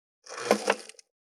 534包丁,厨房,台所,野菜切る,咀嚼音,ナイフ,調理音,まな板の上,料理,
効果音